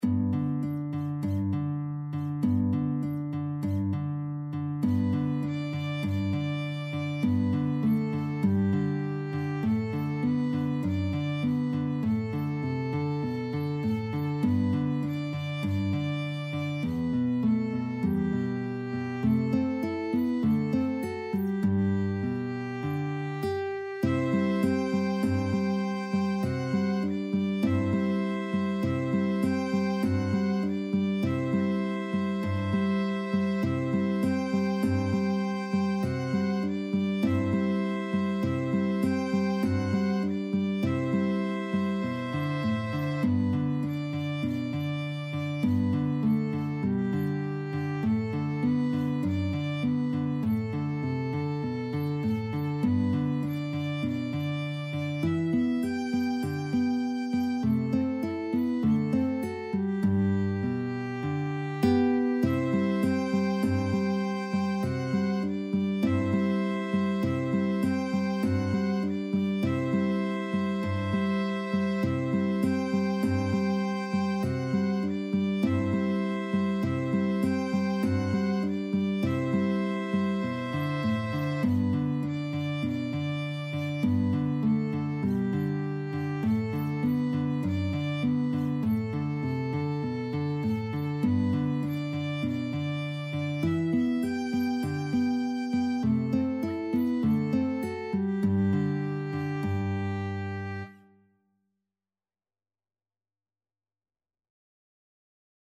Free Sheet music for Violin-Guitar Duet
G major (Sounding Pitch) (View more G major Music for Violin-Guitar Duet )
4/4 (View more 4/4 Music)
Classical (View more Classical Violin-Guitar Duet Music)